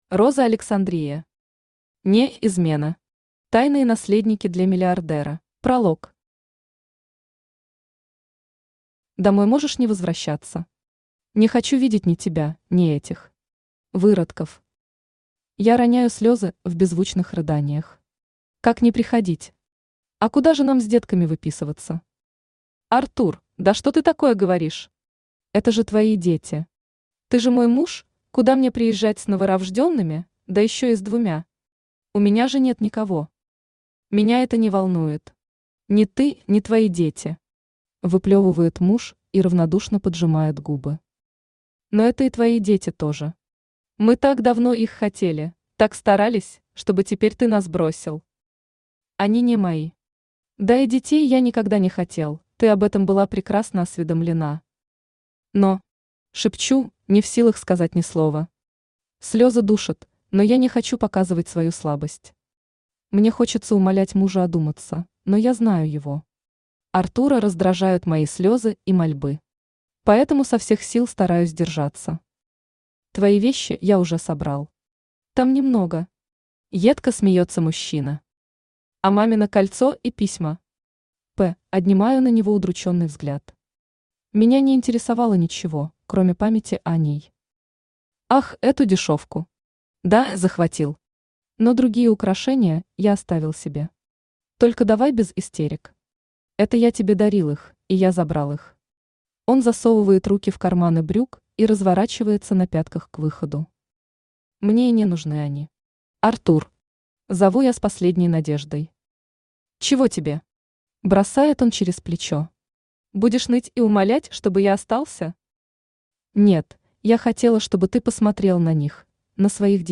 Тайные наследники для миллиардера Автор Роза Александрия Читает аудиокнигу Авточтец ЛитРес.